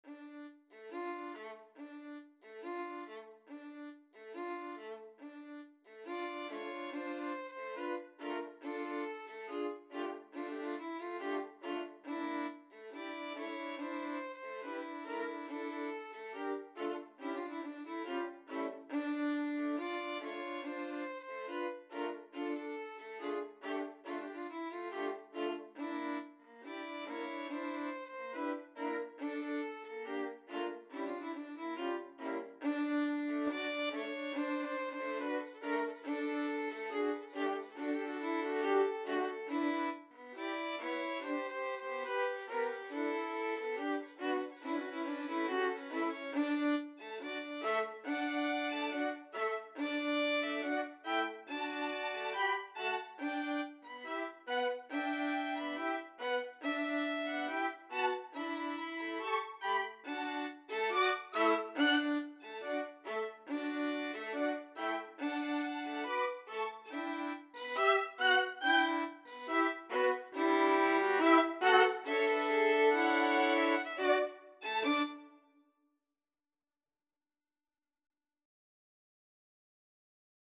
Violin Quartet version
2/4 (View more 2/4 Music)
Classical (View more Classical Violin Quartet Music)